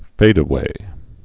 (fādə-wā)